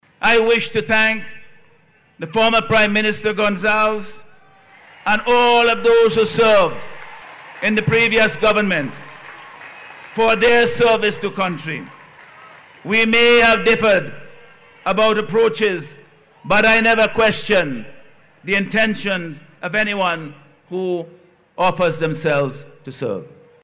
Prime Minister, Dr. Godwin Friday, extended gratitude to the former Prime Minister and members of the administration, during the swearing-in ceremony of his new Cabinet yesterday.